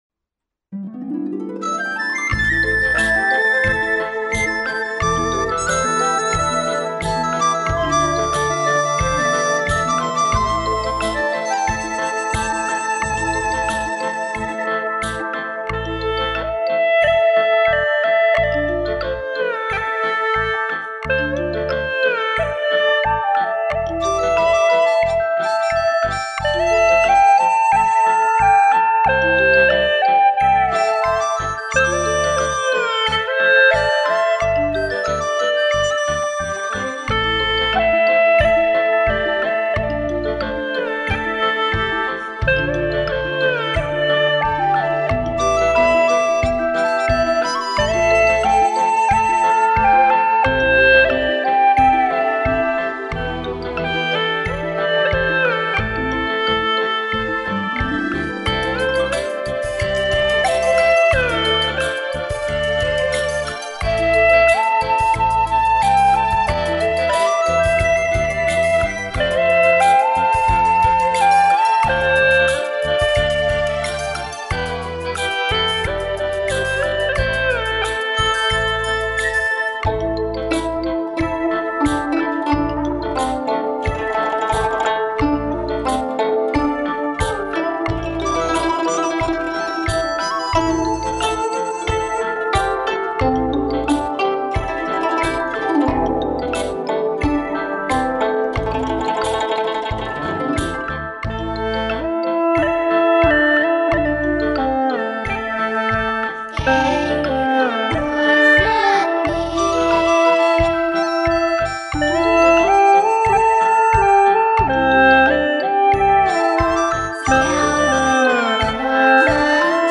调式 : C 曲类 : 流行 此曲暂无教学 点击下载 感恩学葫芦丝、教葫芦丝路上的遇见的诸位老师、丝友。